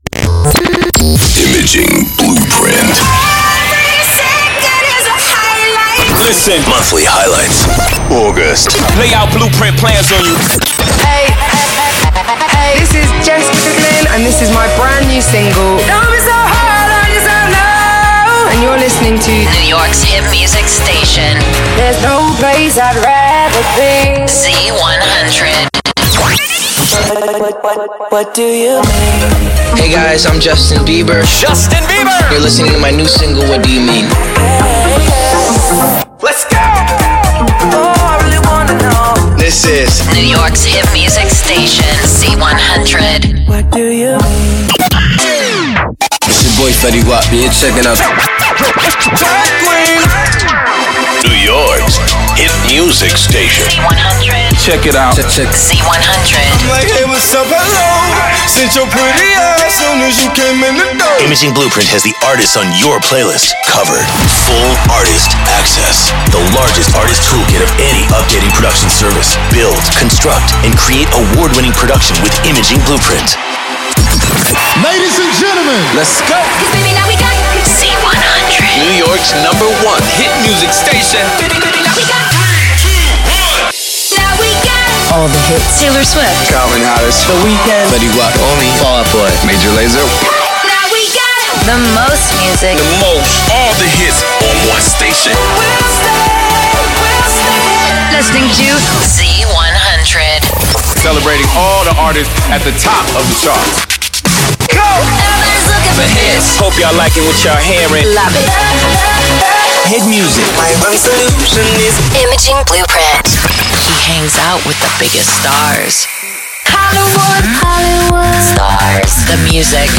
Our client KIIS is used to demonstrate 'IB' production alongside the world famous Z100.